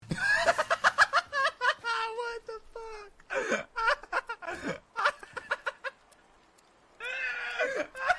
Play, download and share HAHAHAHA WTF original sound button!!!!
hahahaha-wtf.mp3